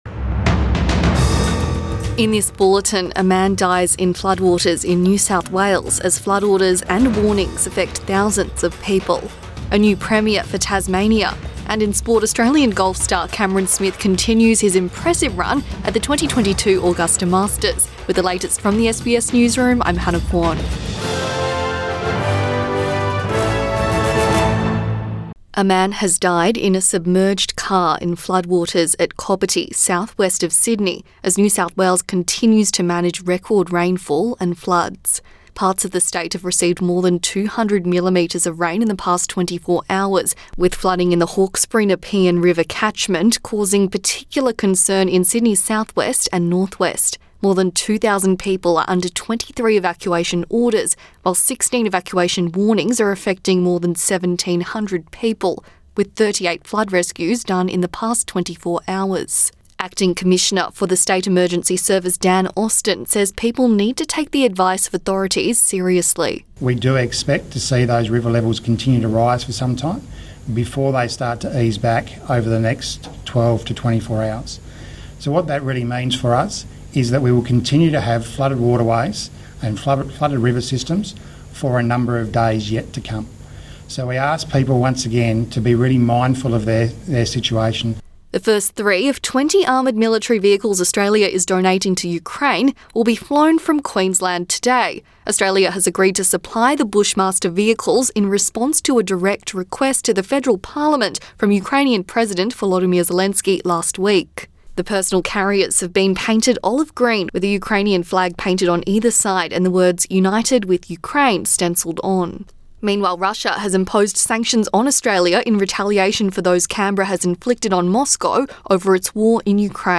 Midday bulletin 8 April 2022